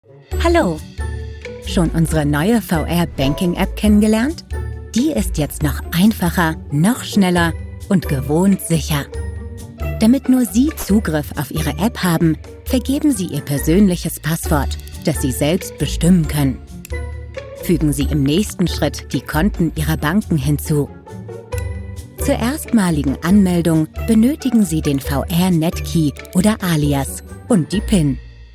Vídeos explicativos
Micrófono: Neumann TLM 103